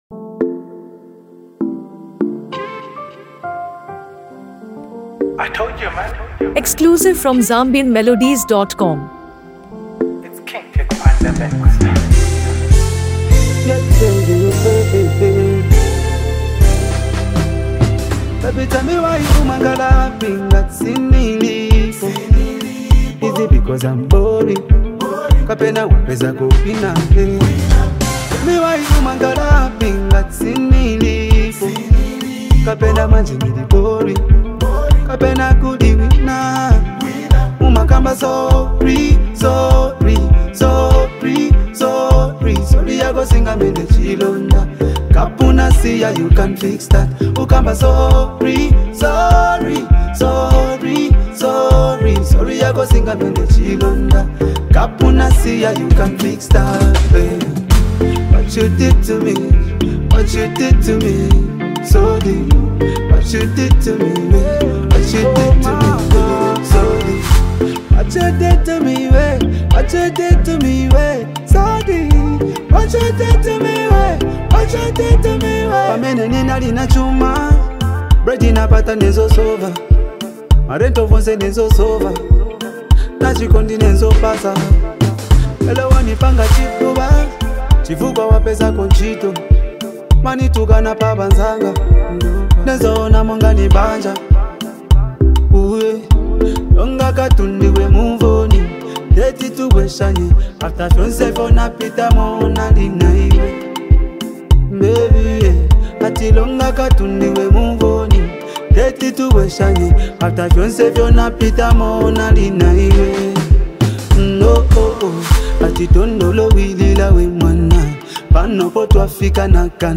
Genre: Afro-Pop